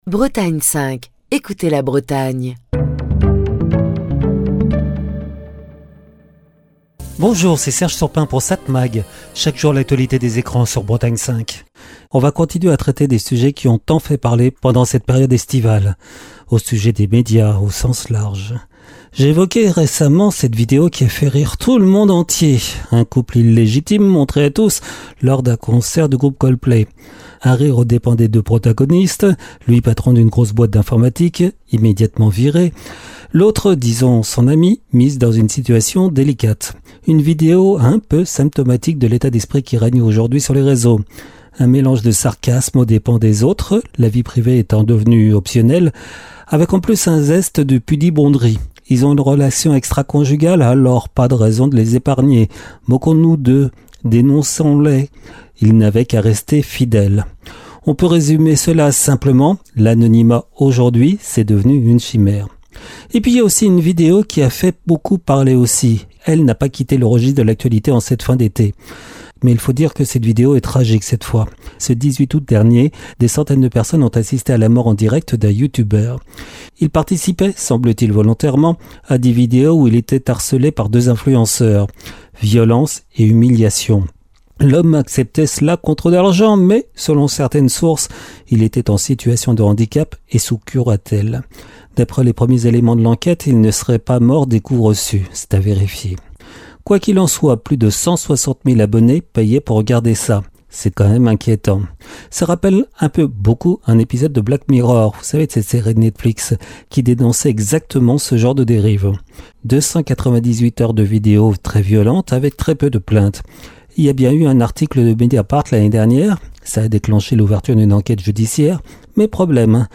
Chronique du 27 août 2025.